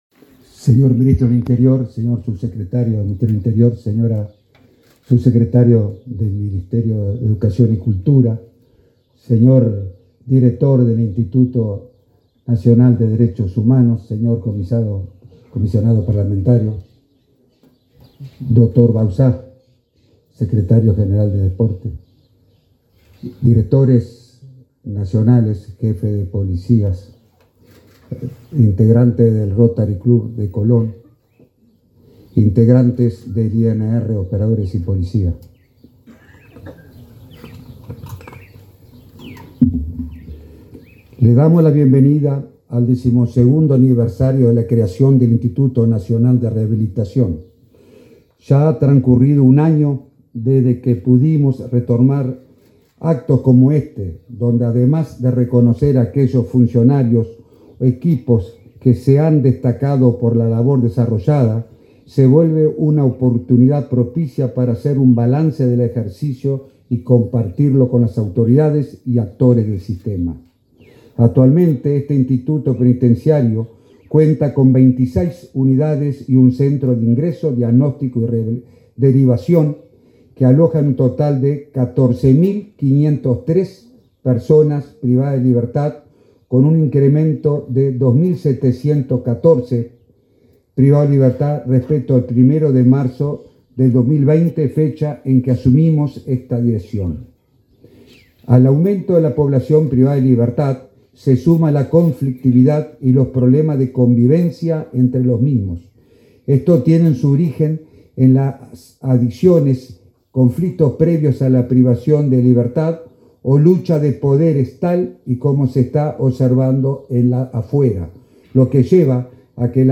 Palabra de autoridades en acto aniversario del INR
Palabra de autoridades en acto aniversario del INR 26/12/2022 Compartir Facebook X Copiar enlace WhatsApp LinkedIn El director del Instituto Nacional de Rehabilitación (INR), Luis Mendoza, y el ministro Luis Alberto Heber participaron del acto por el 12.° aniversario de ese instituto, en la Unidad N.° 2 en el departamento de San José.